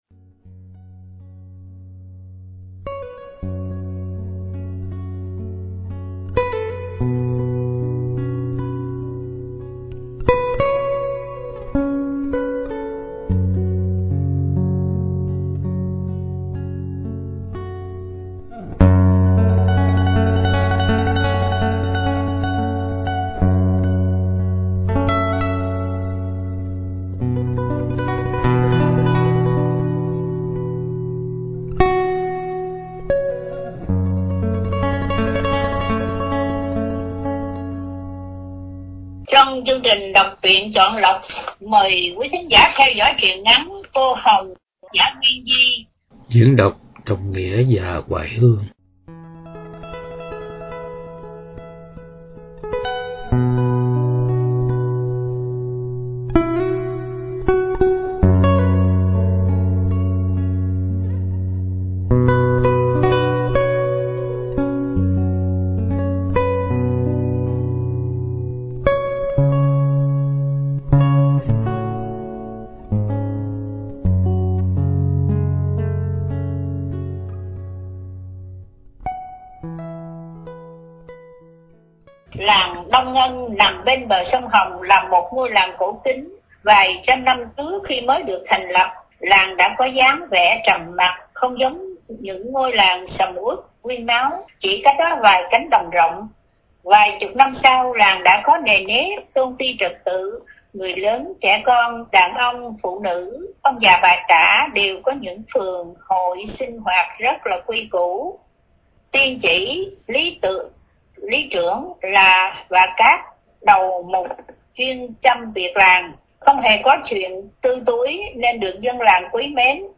Đọc Truyện Chọn Lọc – Truyện Ngắn ” Cô Hồng Cô Bạch” – Tác Giả Quyên Di – Radio Tiếng Nước Tôi San Diego